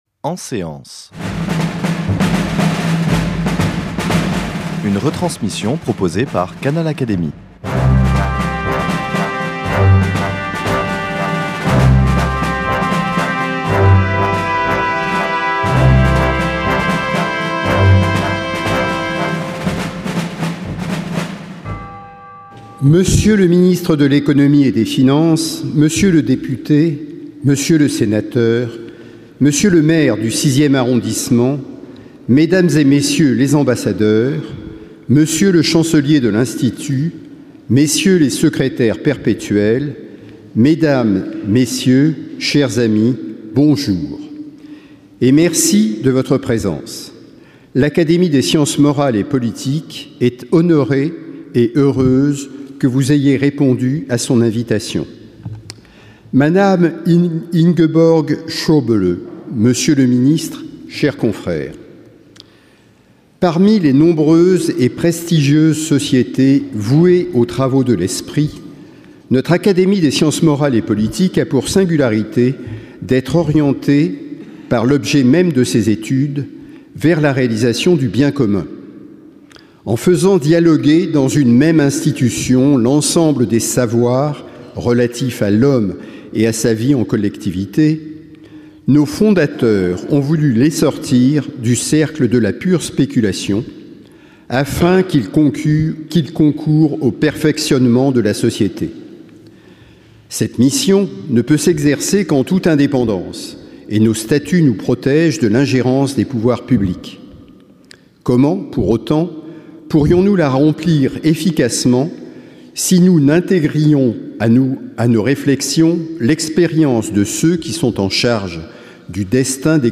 Wolfgang Schäuble, ministre fédéral des Finances d’Allemagne, a été installé le 2 octobre 2017, sous la Coupole de l’Institut, comme membre étranger associé de l’Académie, au fauteuil occupé précédemment par l’universitaire belge Roland Mortier, décédé le 31 mars 2015. Le président de l’Académie, Michel Pébereau, a prononcé le discours d’installation. Il a retracé la riche carrière du nouvel académicien : un itinéraire politique exceptionnel, dans lequel s’entrecroisent l’histoire de l’Allemagne et celle de l’Europe.